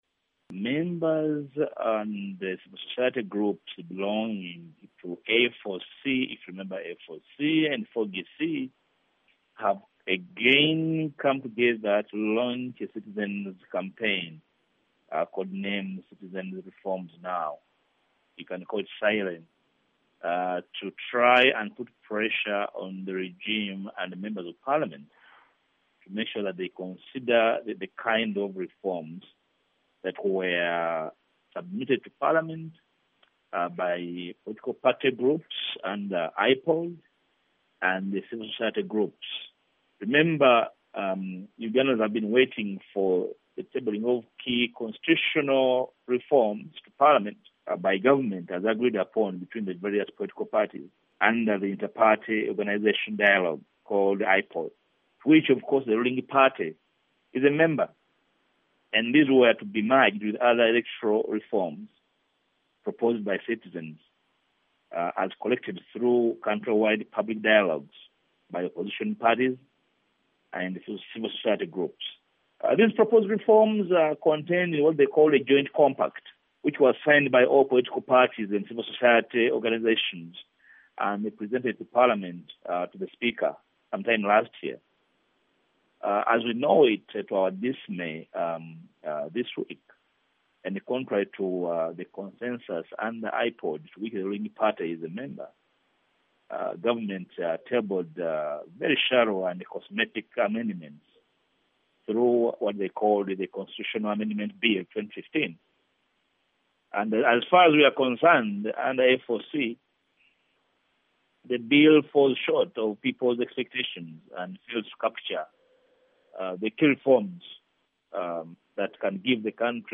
interview with Mathias Mpuga, A Ugandan parliamentarian